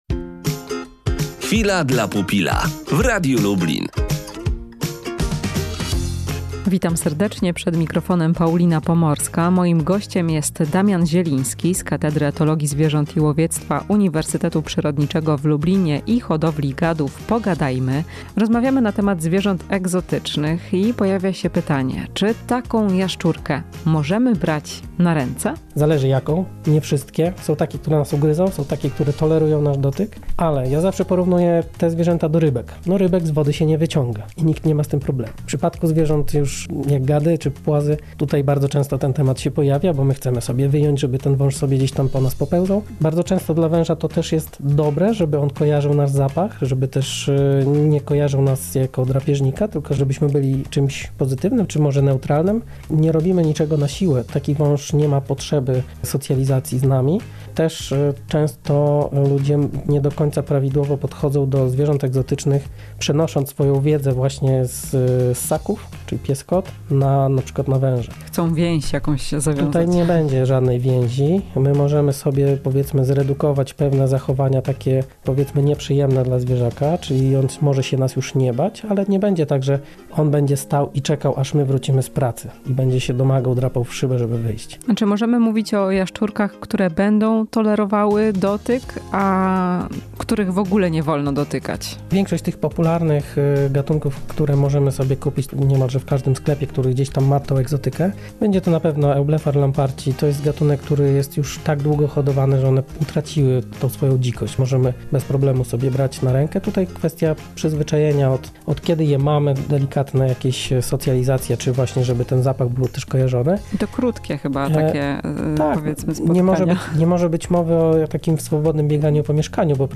Czy jaszczurki i węże możemy brać na ręce? Rozmowa